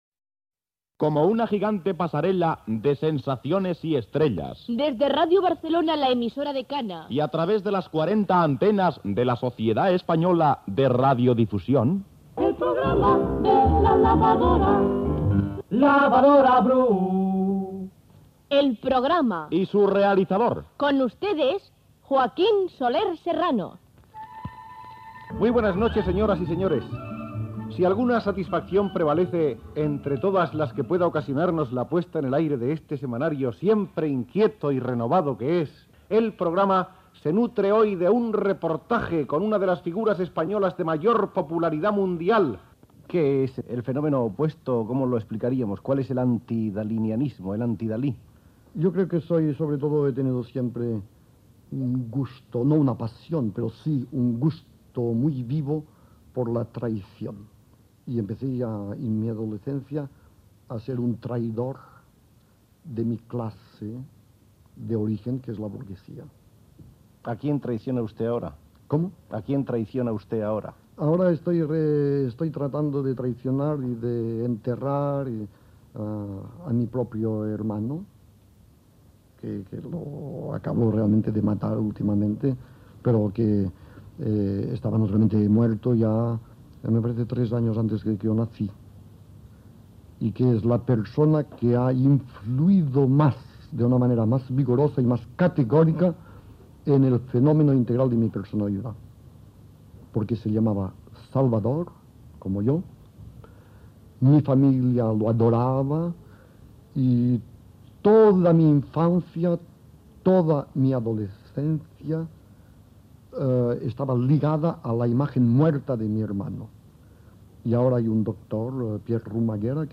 Fragment d'una entrevista a Salvador Dalí a l'espai El Programa de Ràdio Barcelona(1959).